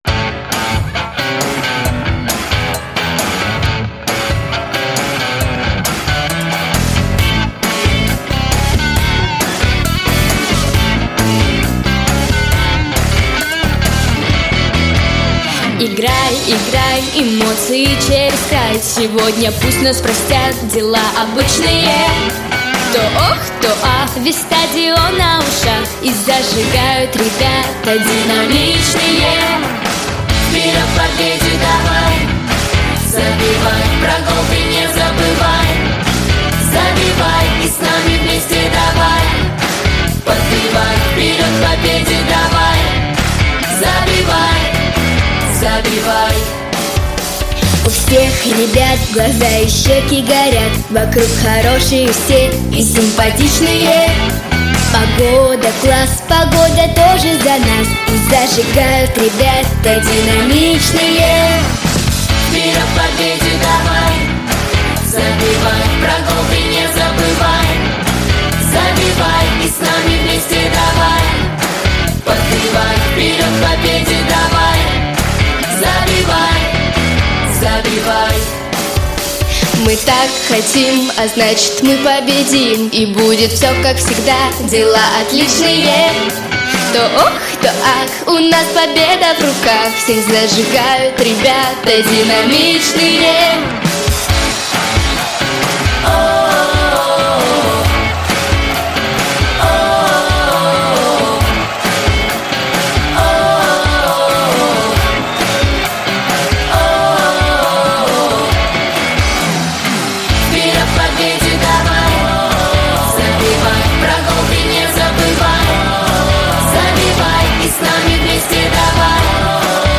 Песни про Спорт 🏅